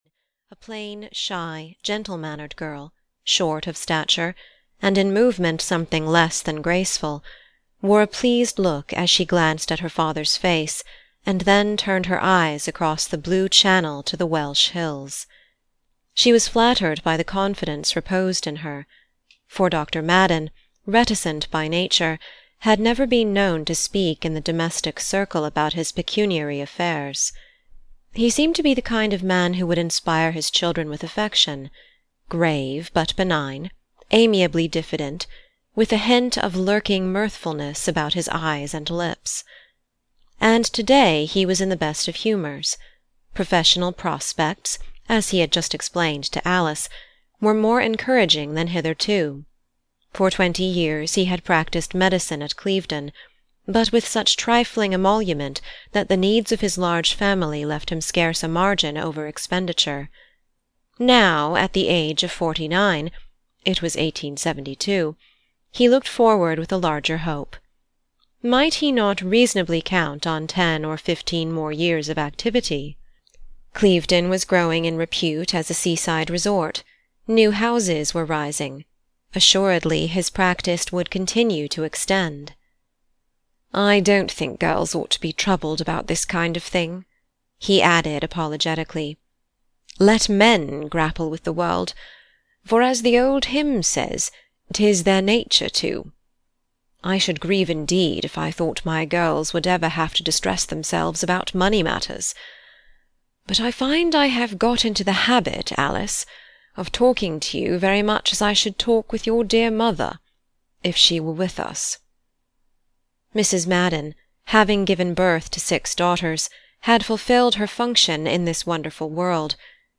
The Odd Women (EN) audiokniha
Ukázka z knihy